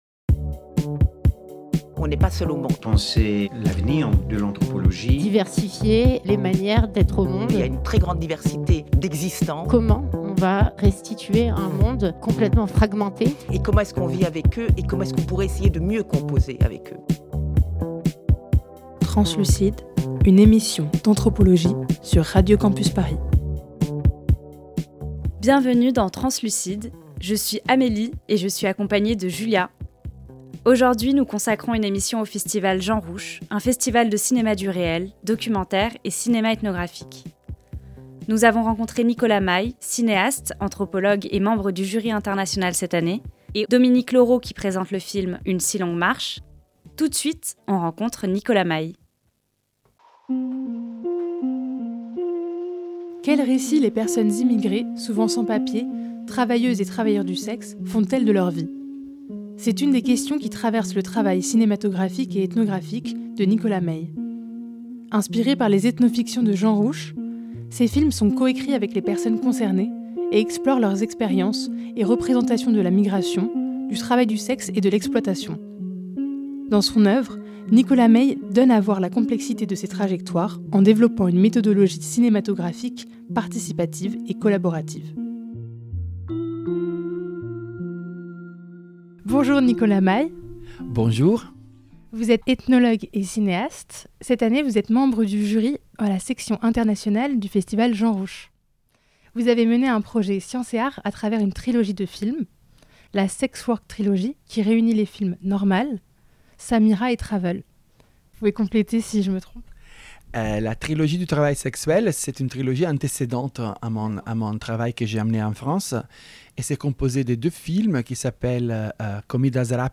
Emission spéciale au Festival Jean Rouch 2023
Ce mois-ci, Translucide s'invite au festival Jean Rouch, festival de cinéma ethnographique et documentaire, qui a lieu du 4 au 21 mai 2023.
Type Entretien